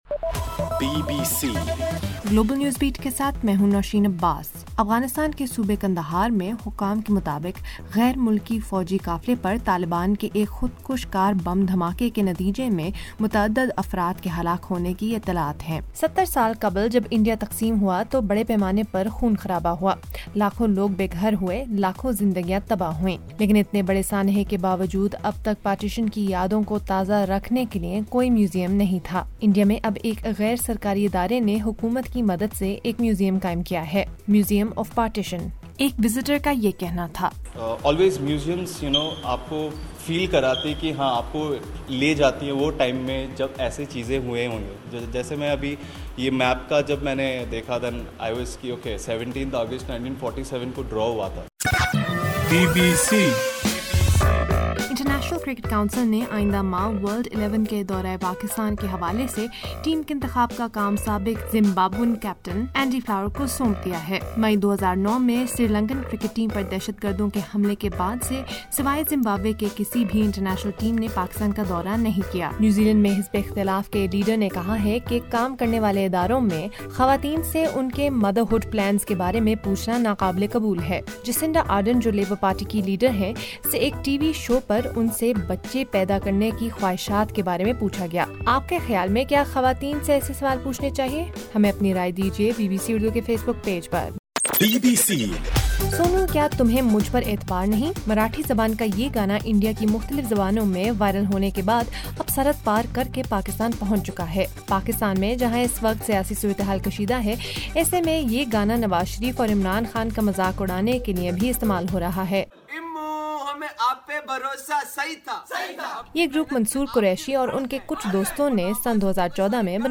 اگست 02 : رات11 بجے کا نیوز بُلیٹن